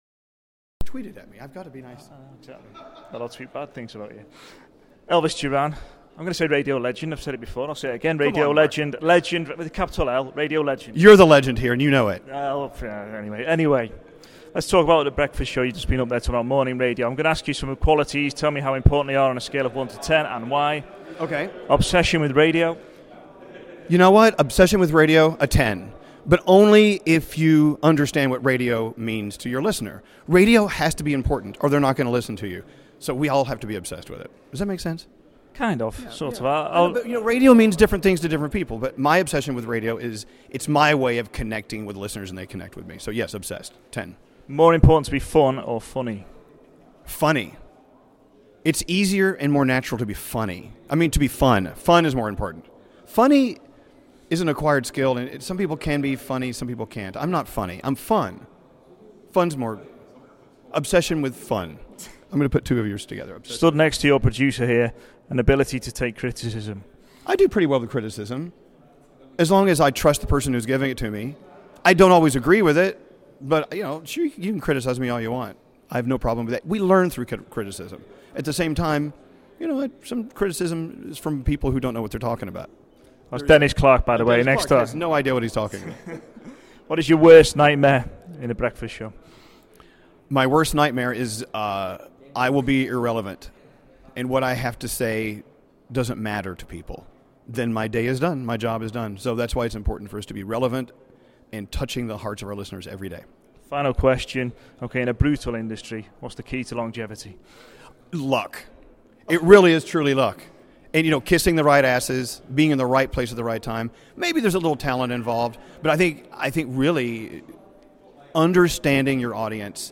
RadioToday Live Interviews / Elvis Duran on what makes mornings